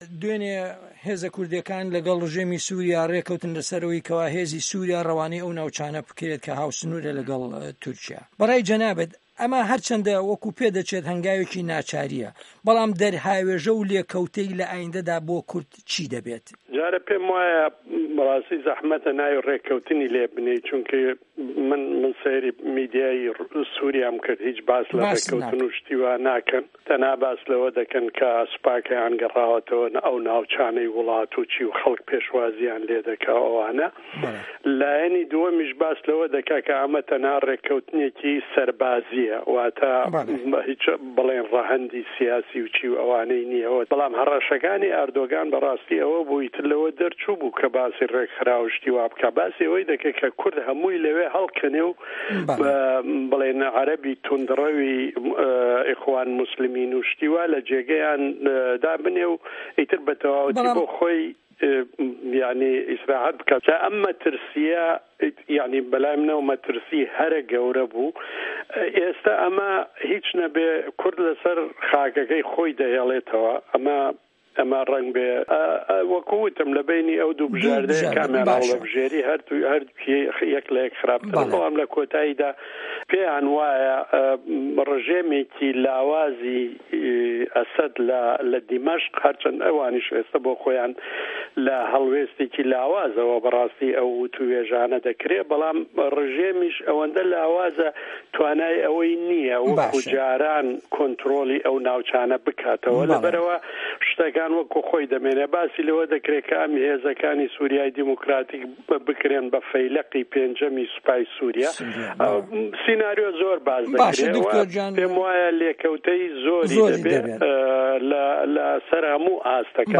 سوریا - گفتوگۆکان